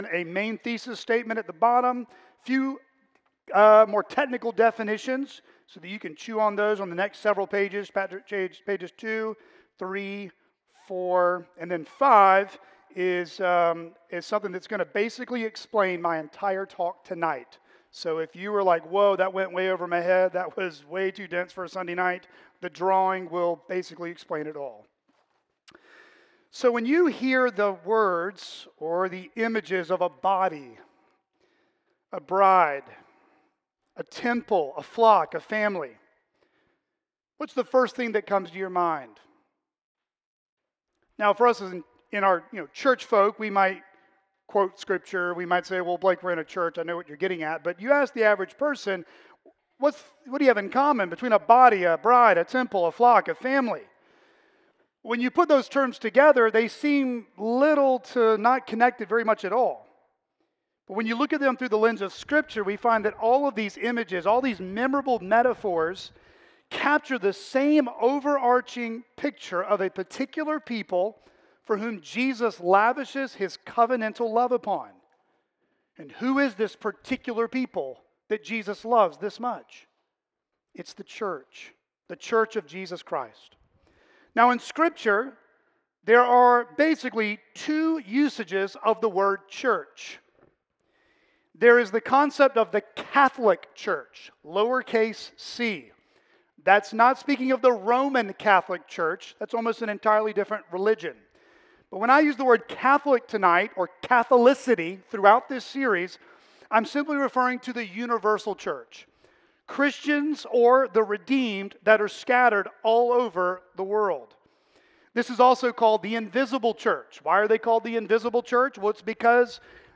This message was delivered on Sunday evening, February 1, 2026, at Chaffee Crossing Baptist Church in Barling, AR.
CCBC Sermons